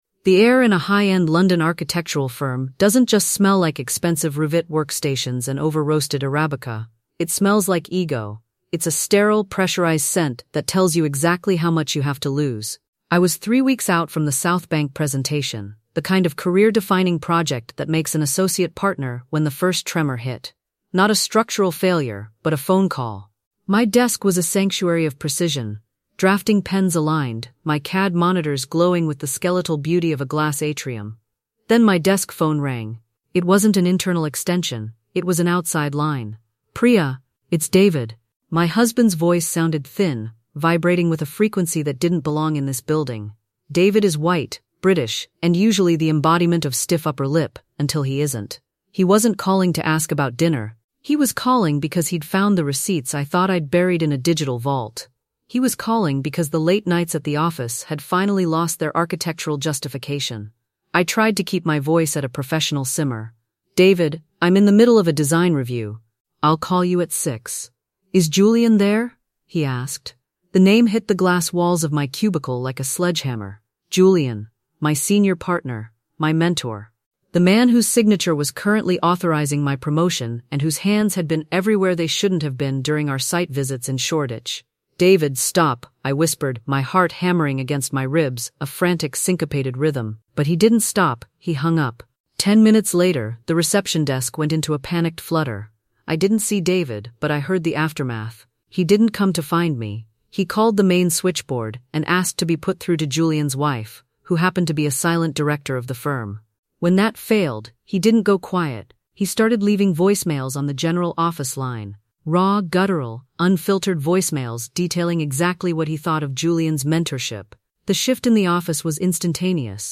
This raw, first-person narrative explores the "point of no return" when the boundary between work and home is irrevocably breached.